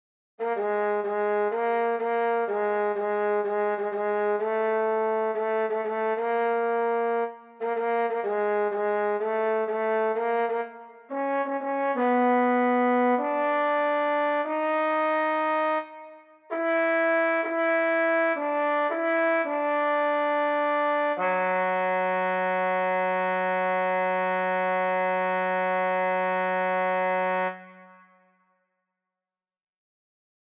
Key written in: F# Major
Type: Barbershop
Each recording below is single part only.